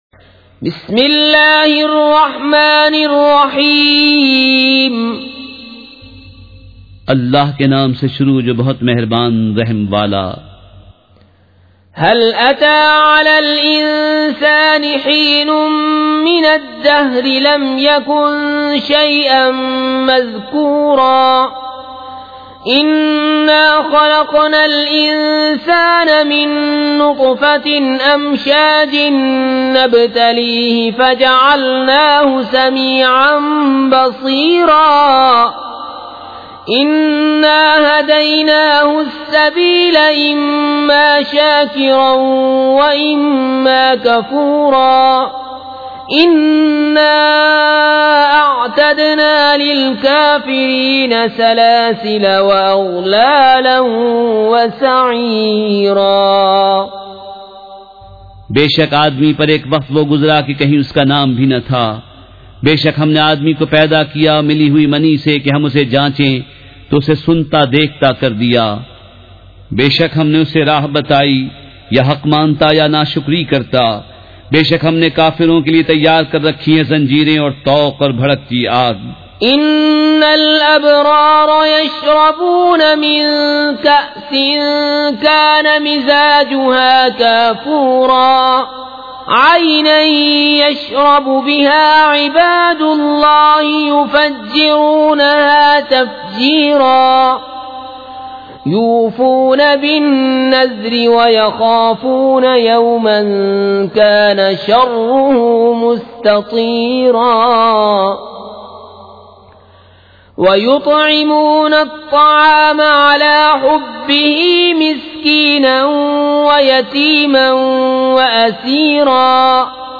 سورۃ الدّھر مع ترجمہ کنزالایمان ZiaeTaiba Audio میڈیا کی معلومات نام سورۃ الدّھر مع ترجمہ کنزالایمان موضوع تلاوت آواز دیگر زبان عربی کل نتائج 1910 قسم آڈیو ڈاؤن لوڈ MP 3 ڈاؤن لوڈ MP 4 متعلقہ تجویزوآراء